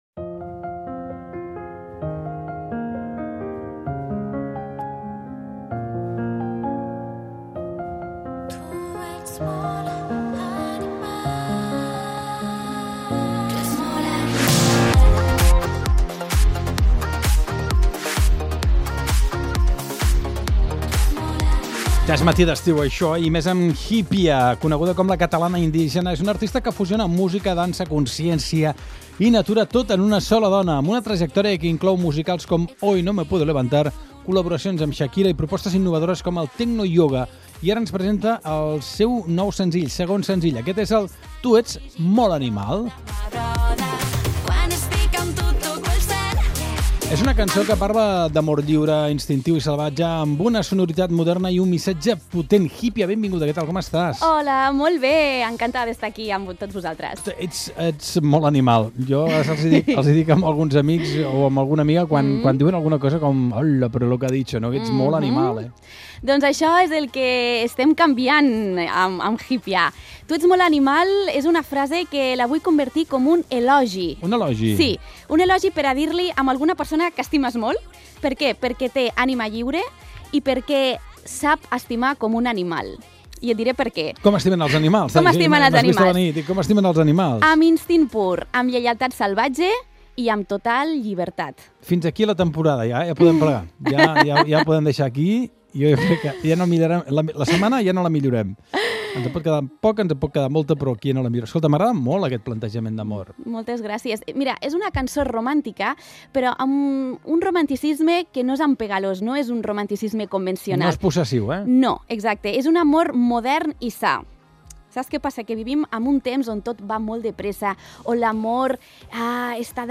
SER Podcast